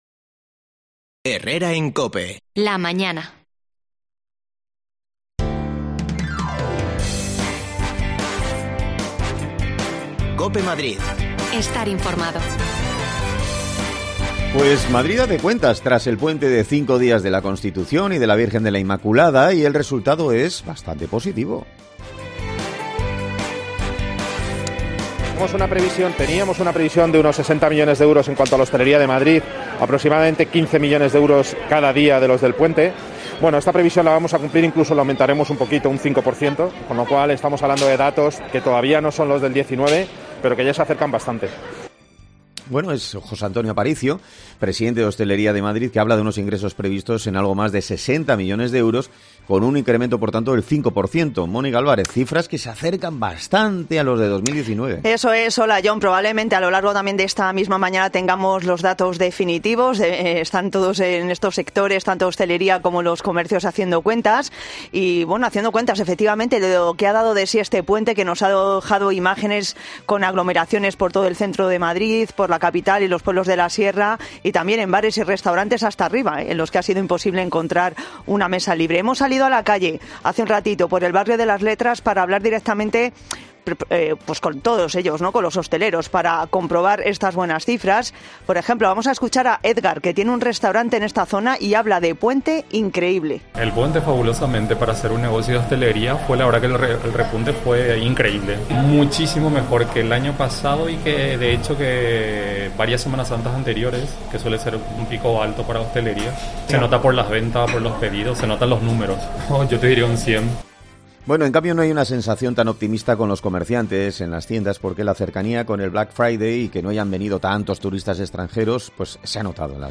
AUDIO: Madrid hace cuentas del puente de la Constitución y la Inmaculada y el balance es muy positivo. Escuchamos a hosteleros y comerciantes